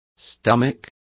Complete with pronunciation of the translation of stomached.